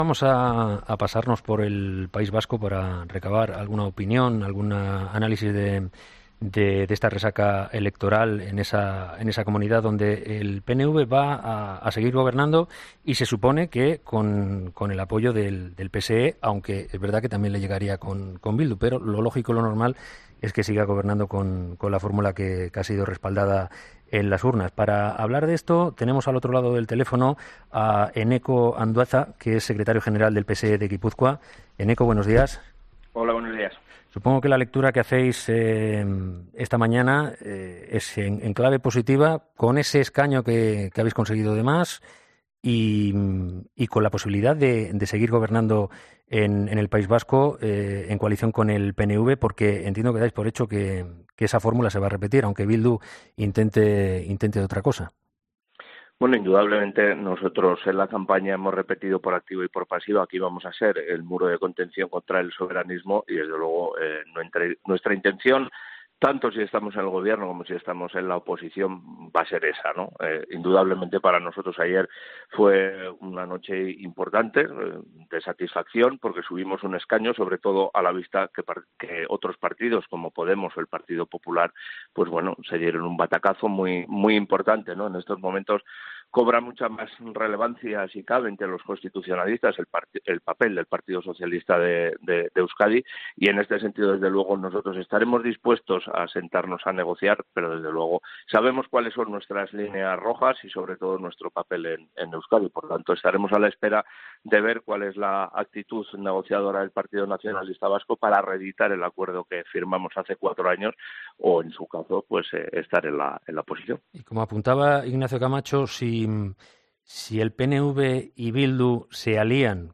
Para hablar de esto, Eneko Andueza ha pasado por los micrófonos de 'Herrera en COPE'.